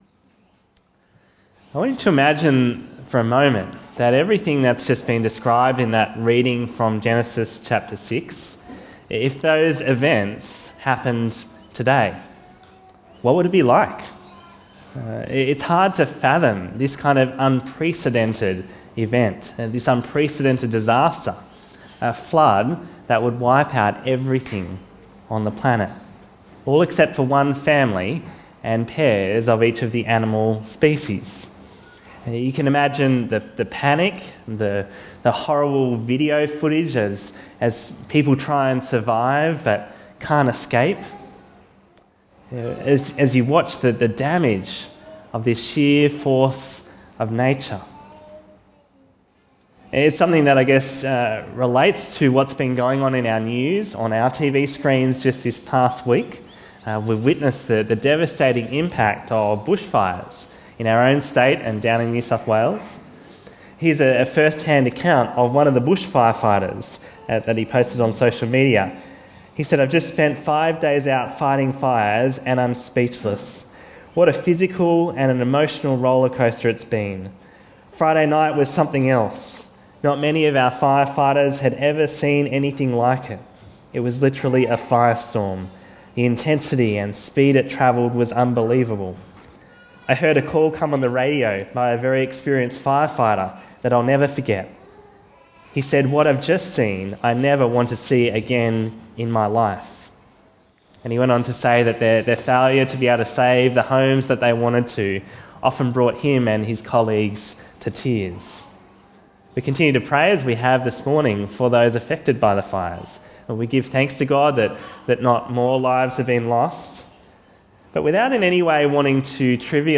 Bible Talks